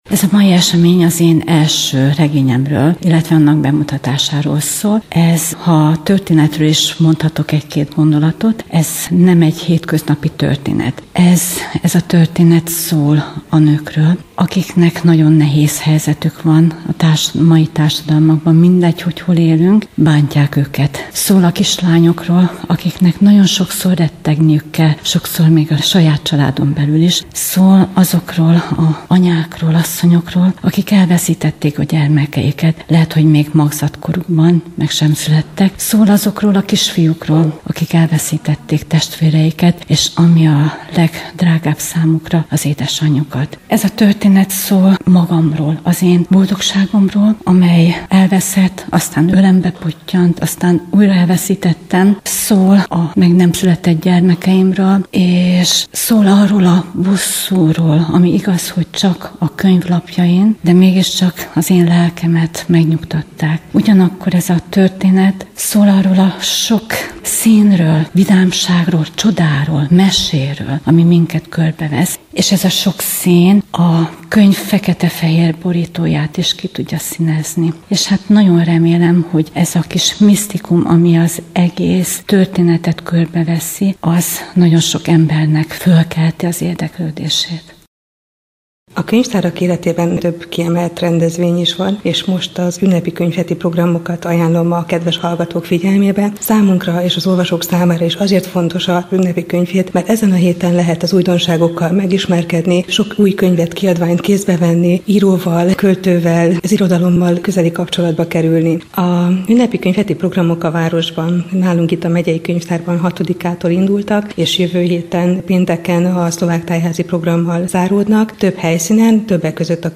Könyvbemutató